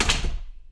sfx_door_close.ogg